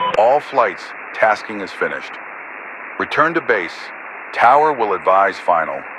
Radio-commandMissionComplete4.ogg